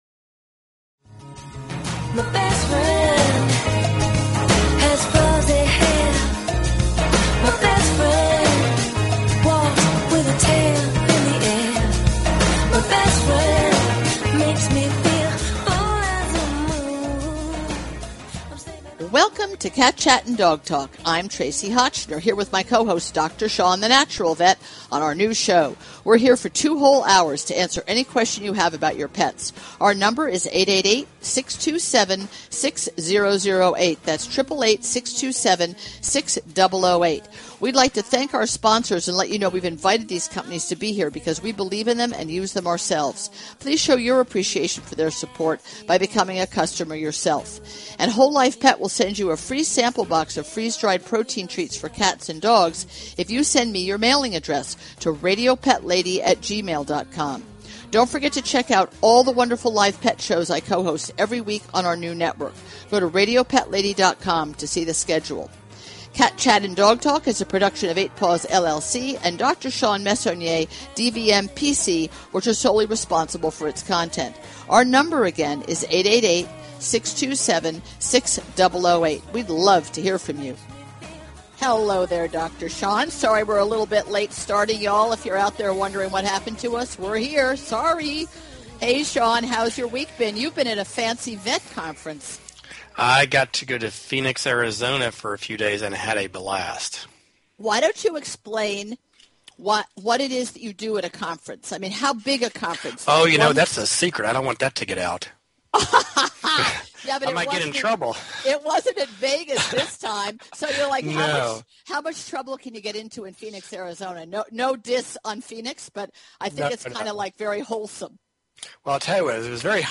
Talk Show Episode, Audio Podcast, Cat_Chat_and_Dog_Talk and Courtesy of BBS Radio on , show guests , about , categorized as
Now she is here every Tuesday night – Live! - to answer all your dog & cat questions!